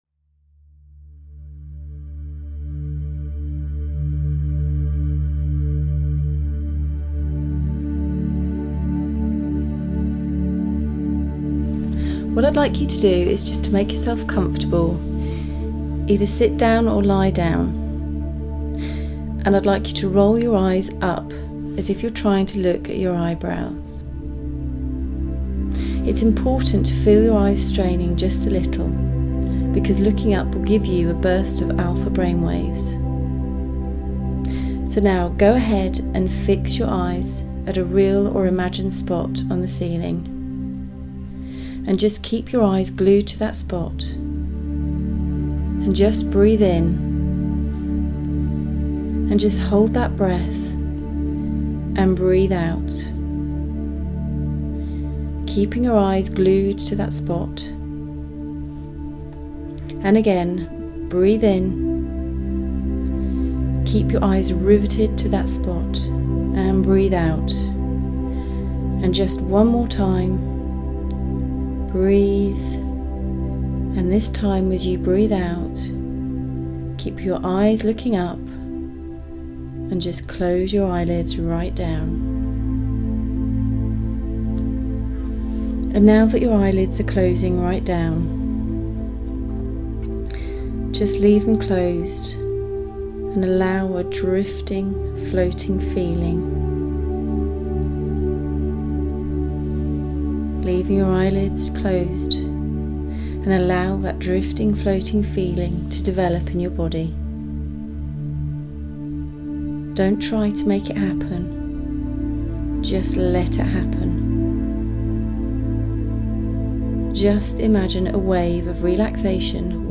Most of us don’t drink enough for various reasons and, because of that, I have recorded a hypnosis for you to listen to, to help increase your intake.